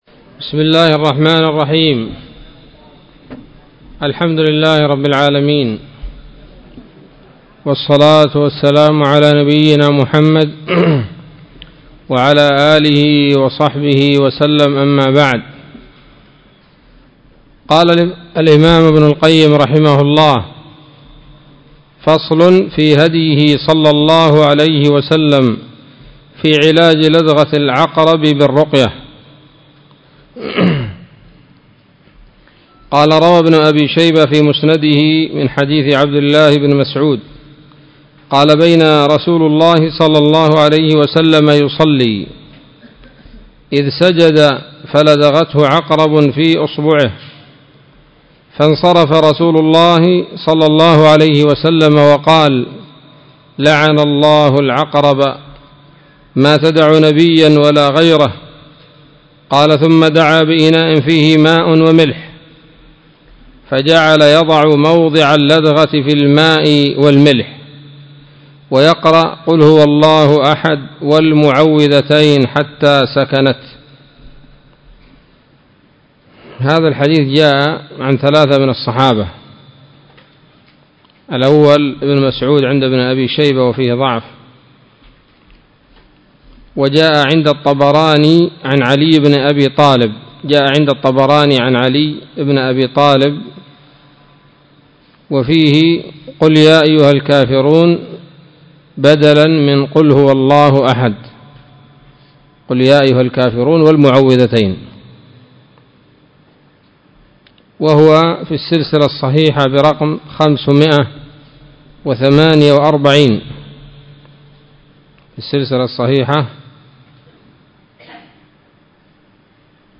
الدرس الحادي والخمسون من كتاب الطب النبوي لابن القيم